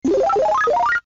Tesla Lock Sounds & Chimes Collection: Movies, Games & More - TeslaMagz
Power Up Mario.wav